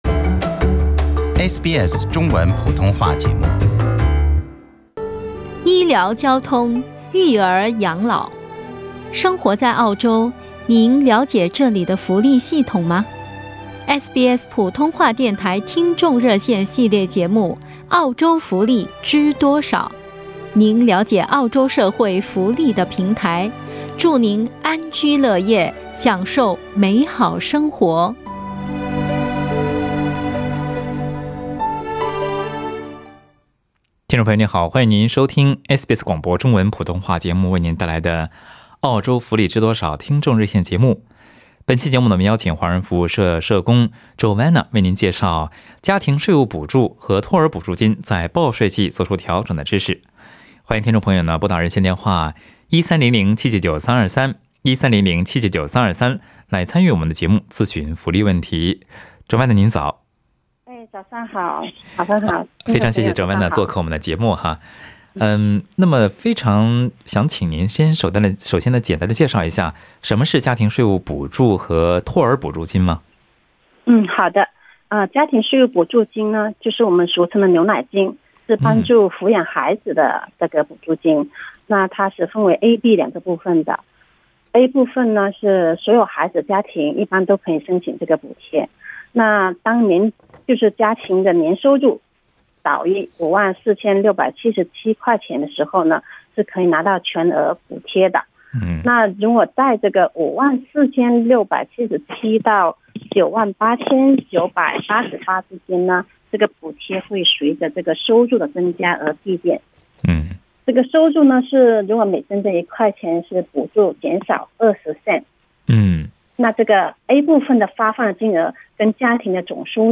听众朋友还在节目中咨询了领取养老金的资产测试等问题。